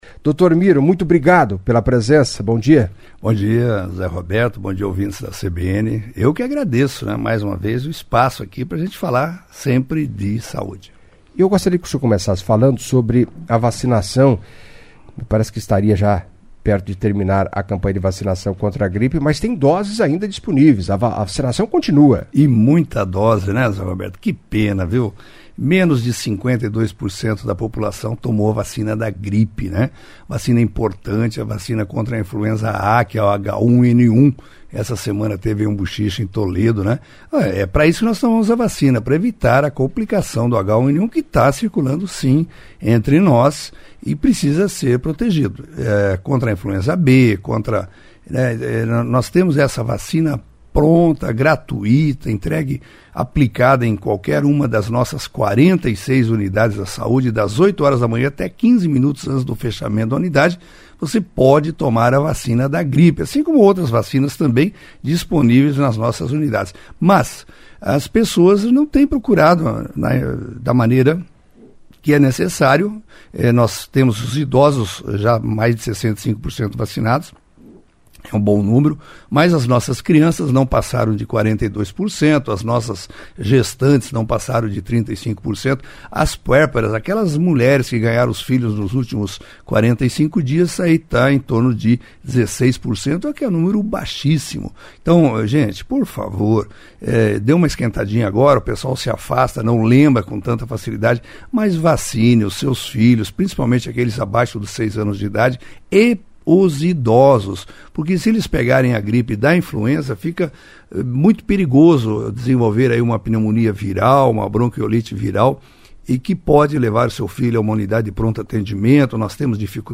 Em entrevista à CBN nesta segunda-feira (26) o secretário de Saúde de Cascavel, Miroslau Bailak, disse que segue baixa a procura pela vacina contra a gripe no município. Por outro lado, antecipou a informação, trouxe um balanço positivo das ações da pasta neste primeiro semestre de 2023, acompanhe.